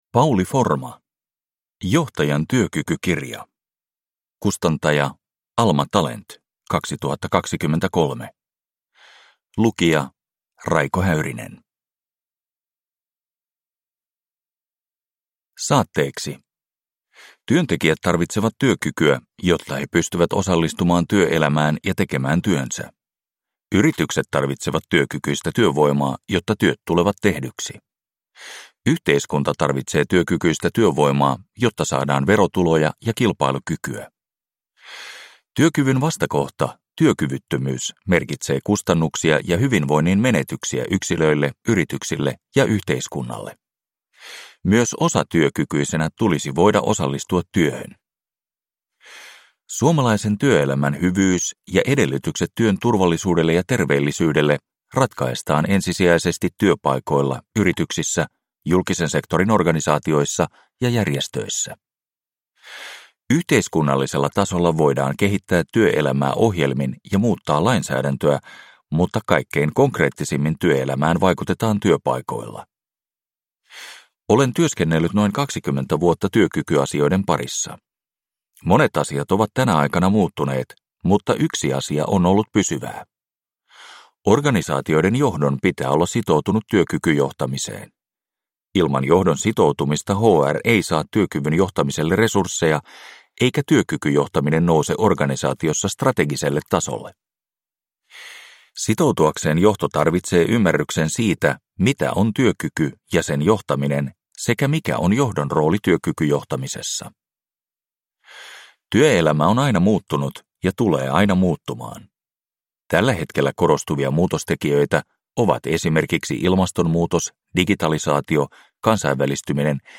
Johtajan työkykykirja – Ljudbok – Laddas ner